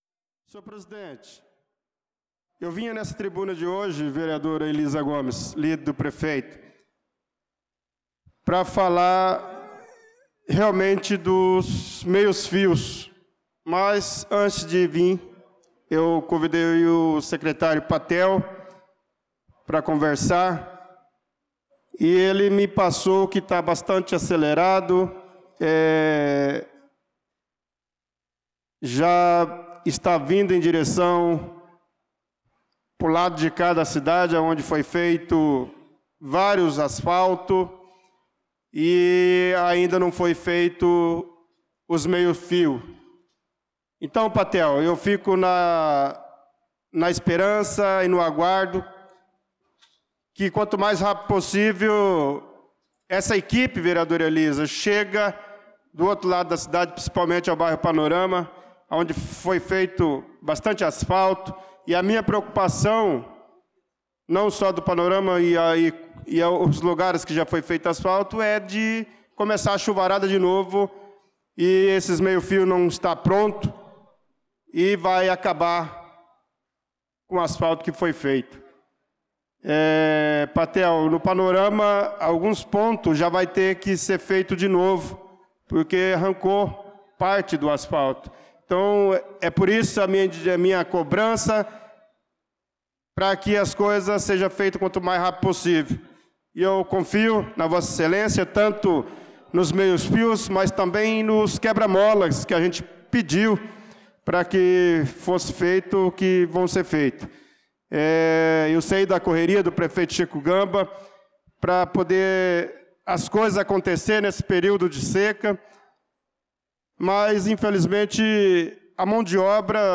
Pronunciamento do vereador Bernardo Patrício na Sessão Ordinária do dia 07/07/2025.